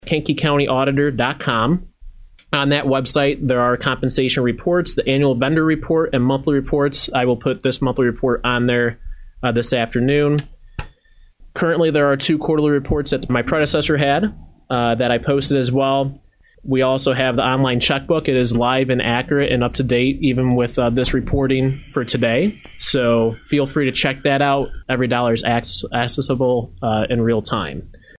During this morning’s meeting of the County Board’s Finance Committee, County Auditor Colton Ekhoff announced his office has a new website available